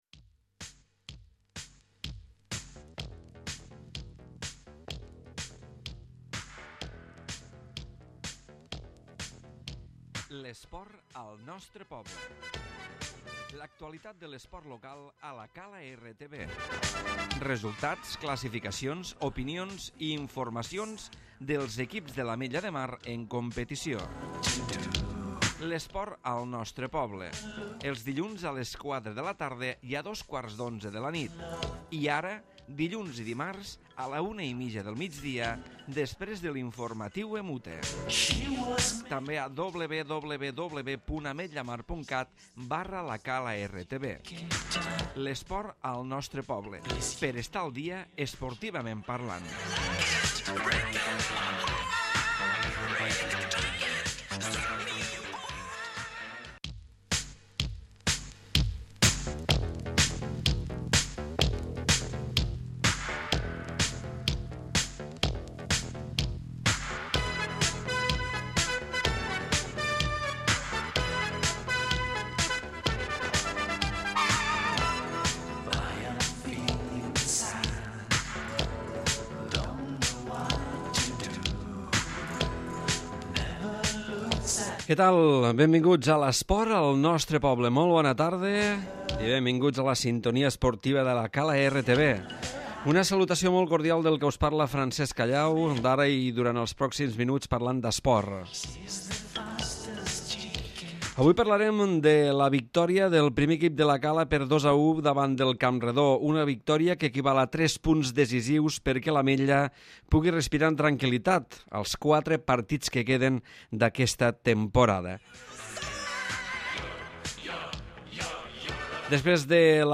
Programa esportiu